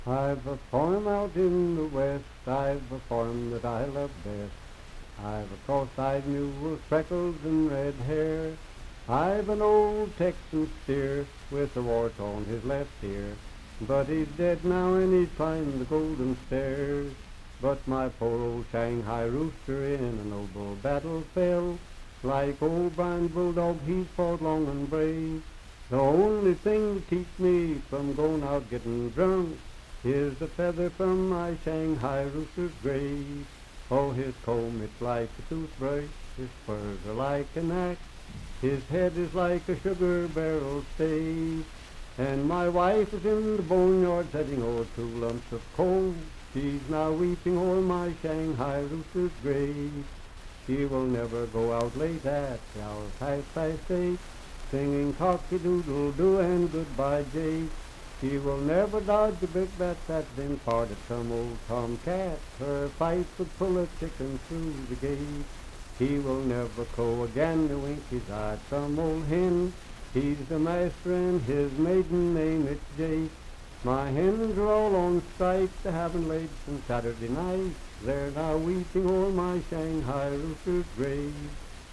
Unaccompanied vocal music
Miscellaneous--Musical
Voice (sung)
Pendleton County (W. Va.), Franklin (Pendleton County, W. Va.)